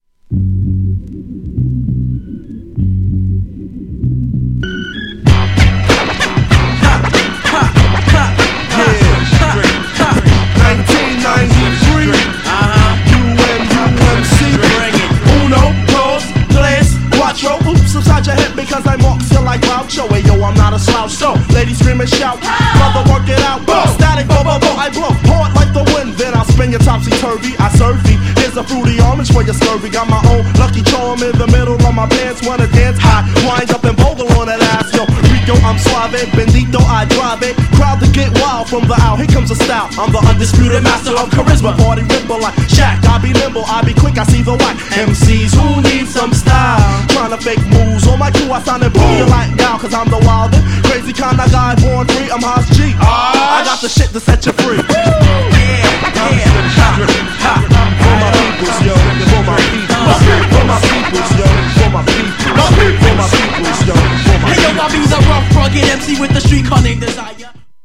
GENRE Hip Hop
BPM 96〜100BPM
FUNKYなアレンジ
アツイMICリレー # アツイフロウ # コール&レスポンスもキマってます